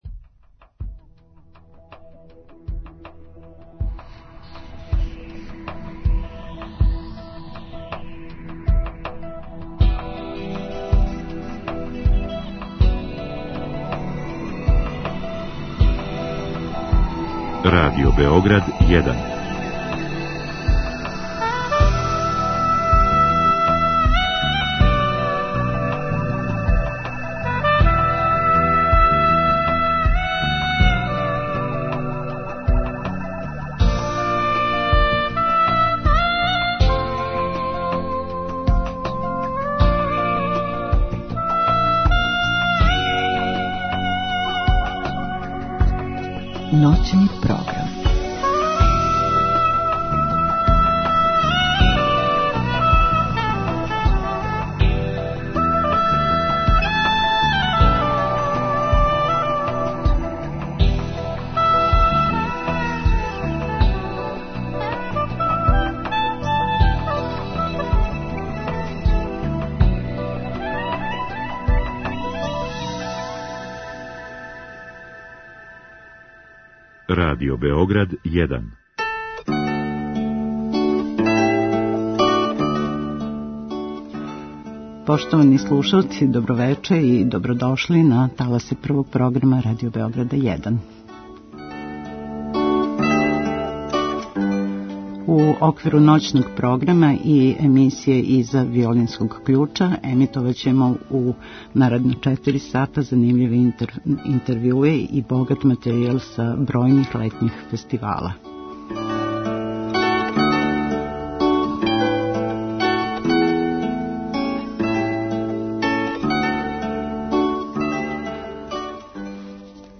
У првом делу емисије наши гости у студију биће